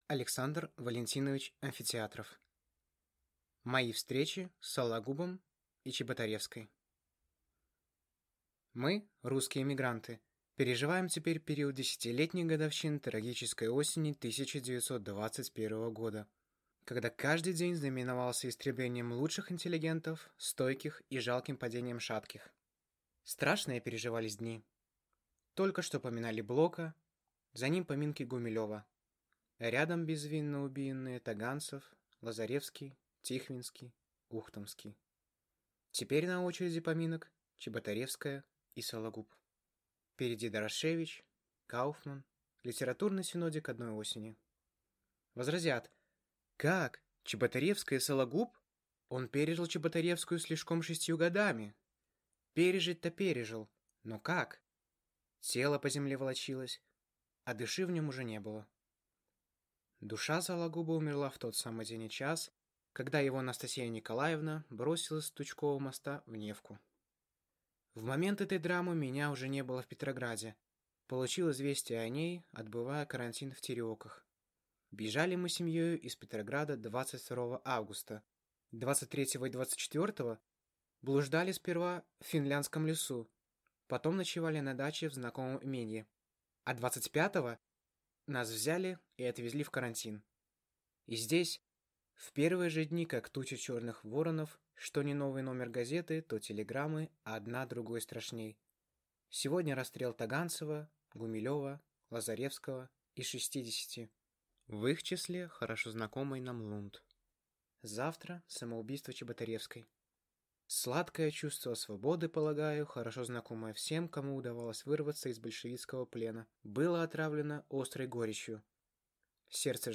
Аудиокнига Мои встречи с Сологубом и Чеботаревской | Библиотека аудиокниг